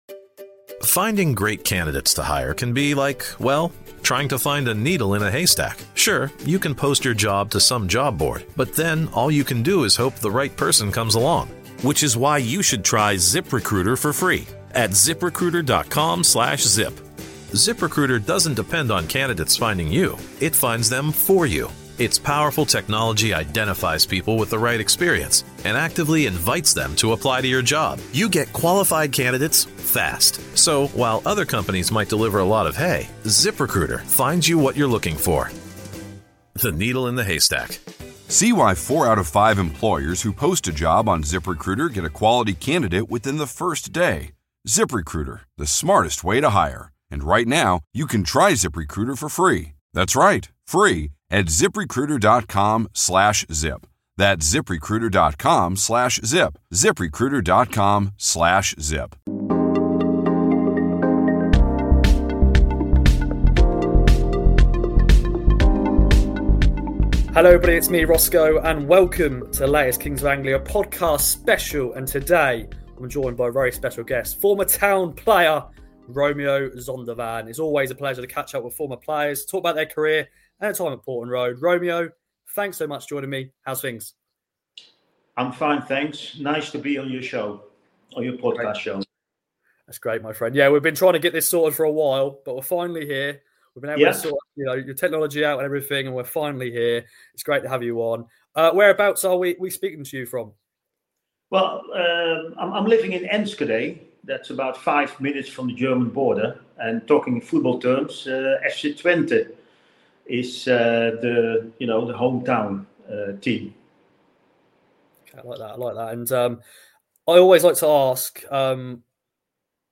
KOA special